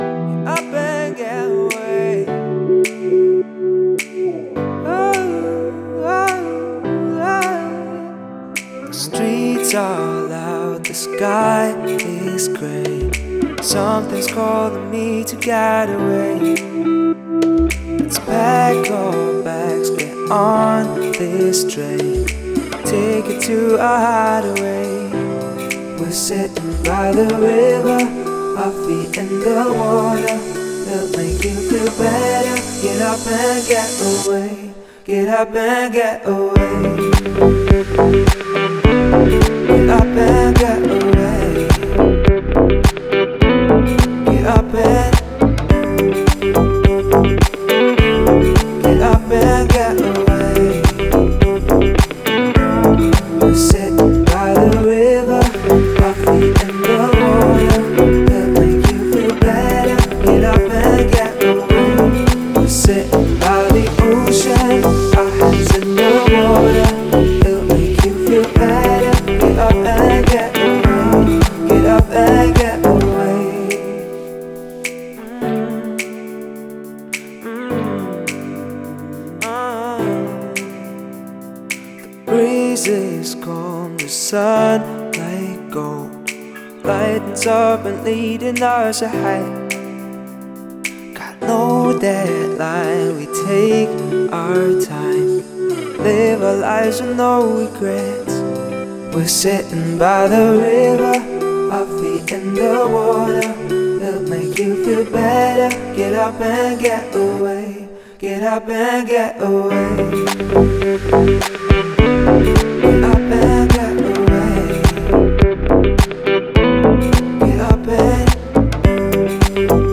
это динамичная электронная трек